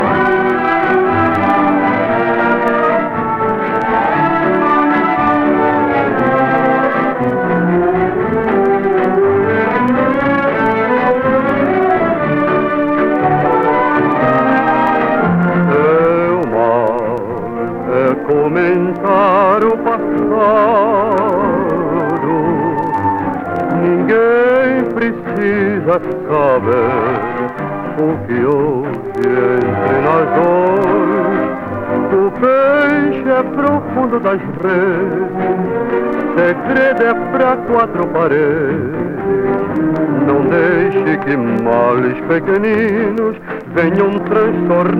Pop, Vocal, World, Samba　Brazil　12inchレコード　33rpm　Mono